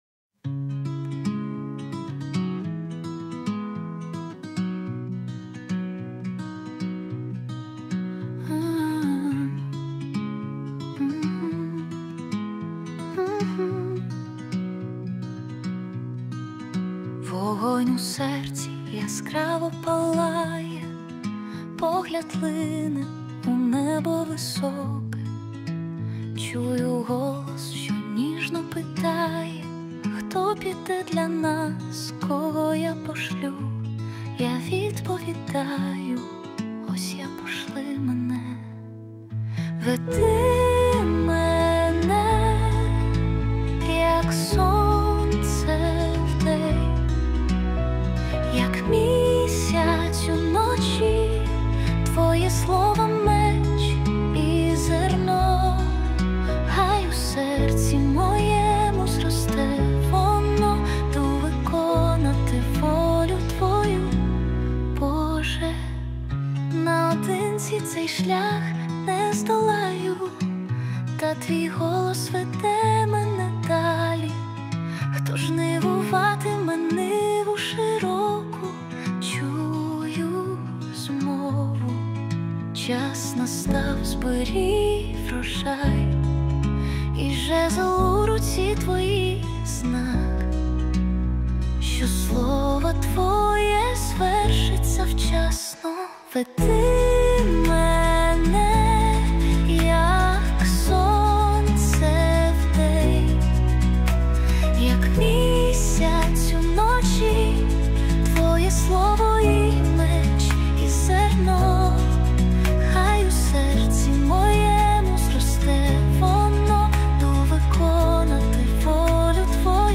песня ai